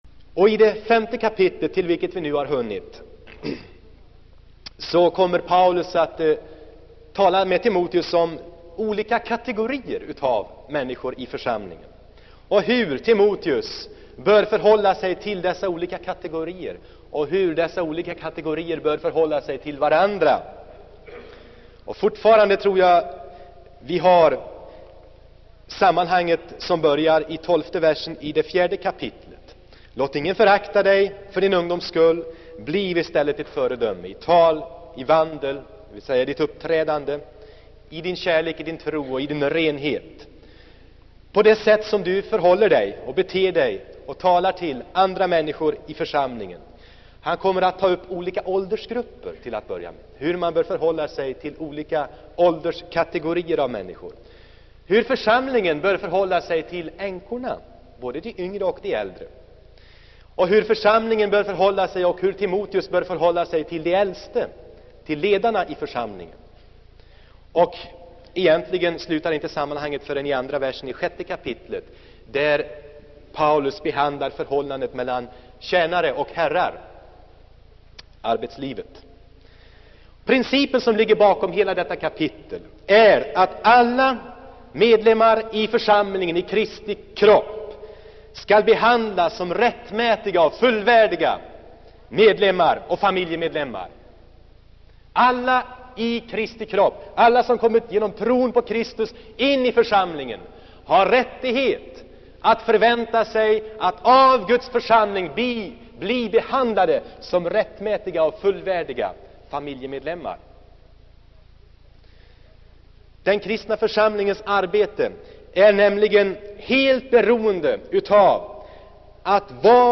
Inspelad i Saronkyrkan, Göteborg 1977-01-16.